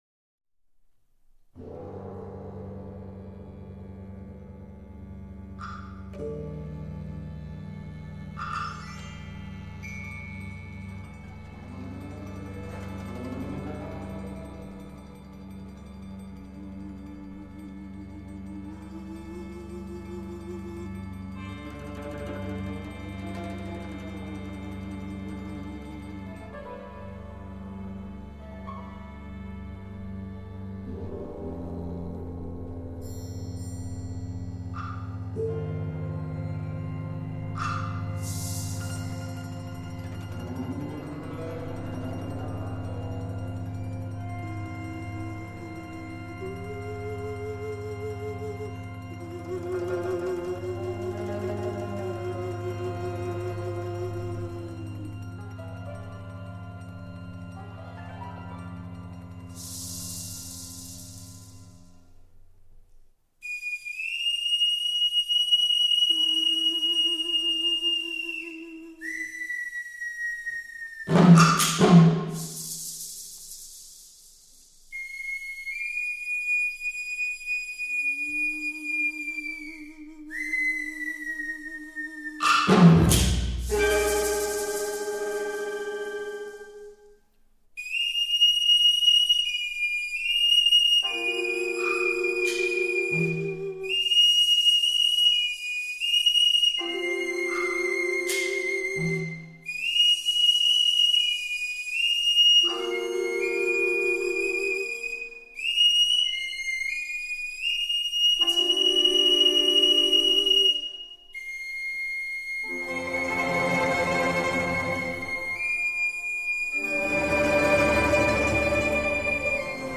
骨笛与乐队